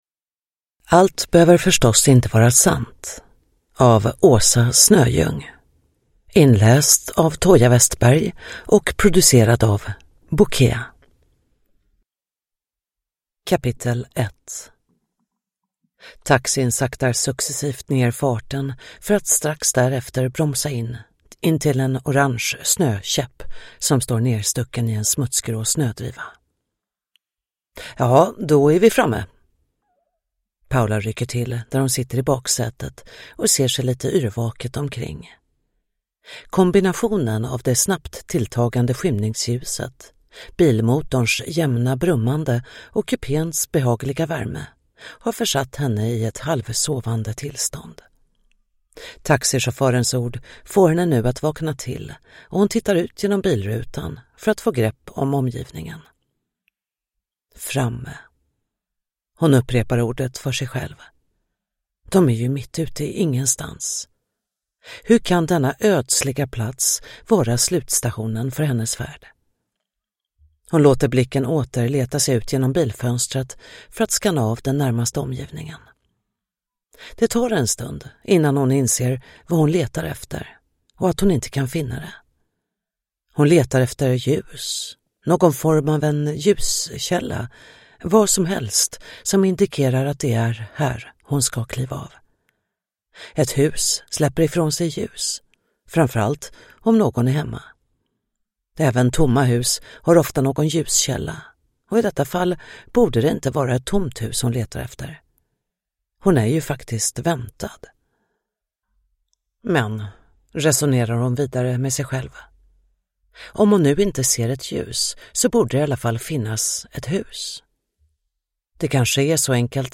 Allt behöver förstås inte vara sant (ljudbok